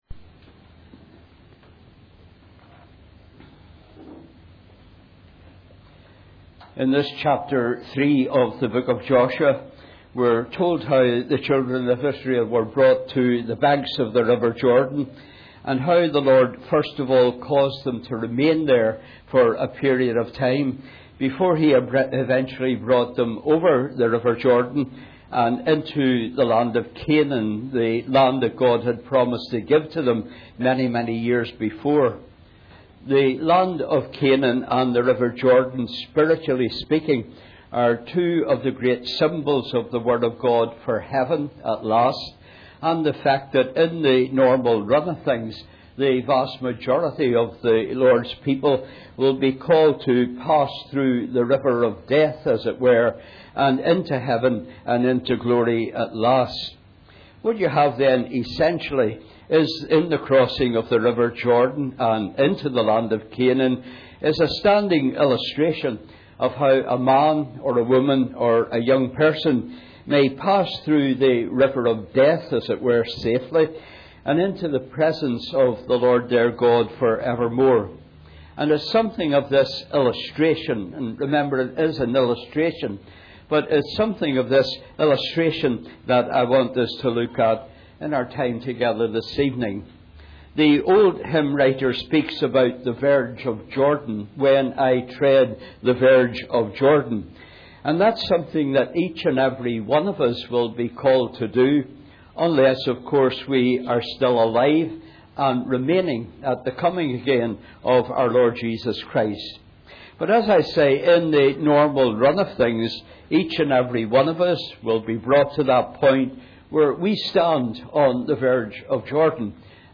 Preached on the 18th of September 2011.